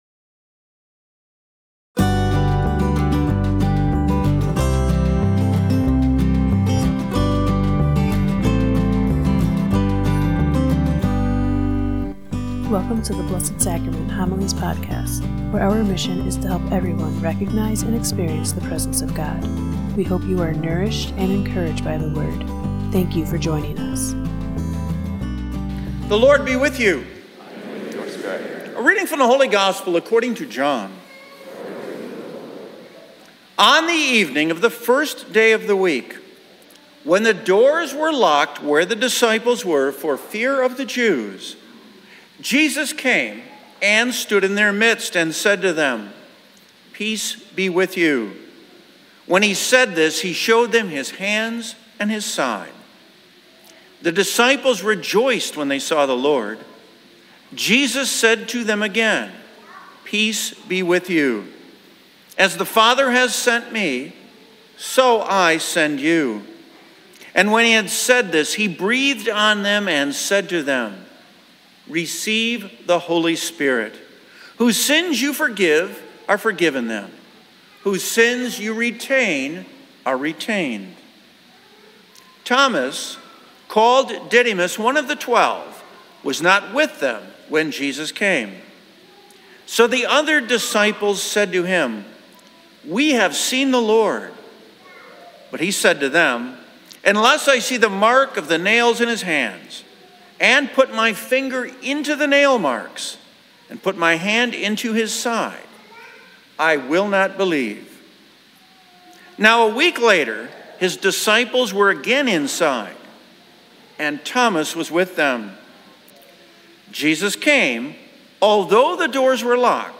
NOTE: The kids at Mass were praying loudly!